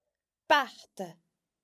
Listen to the a sound within a Gaelic word: